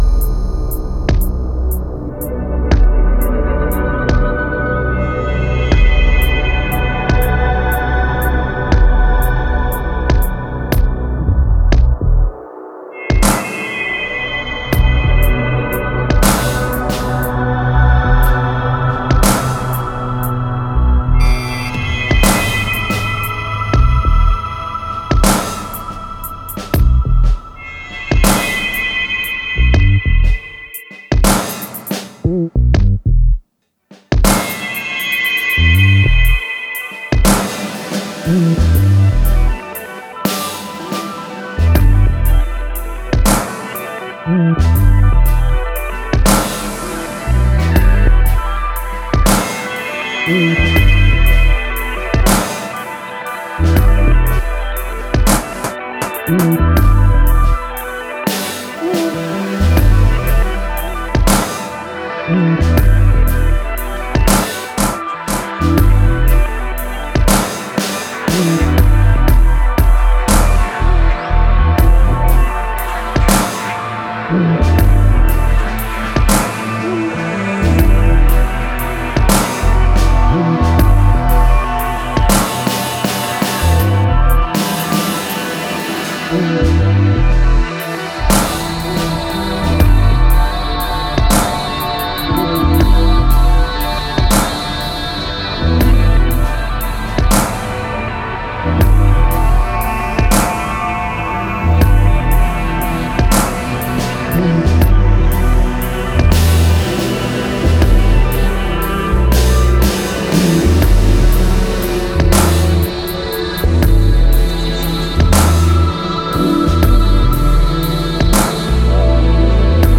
Genre: IDM, Glitch, Downtempo.